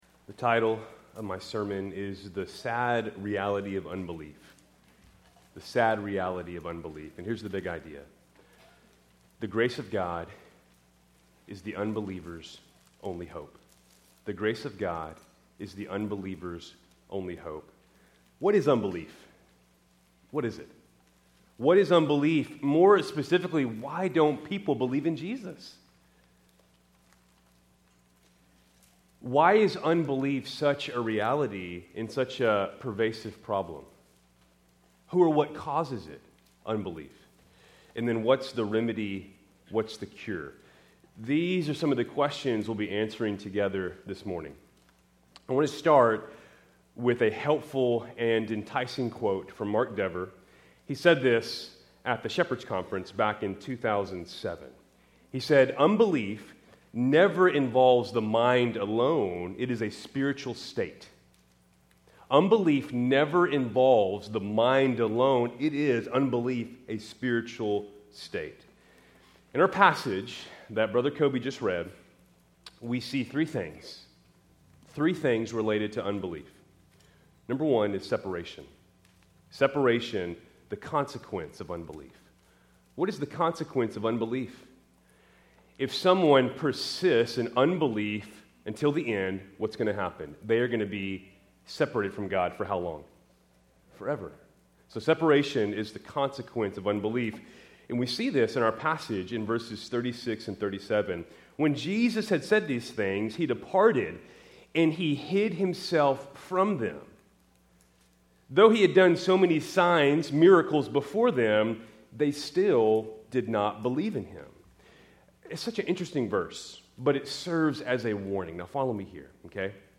Keltys Worship Service, May 18, 2025 Graduate Sunday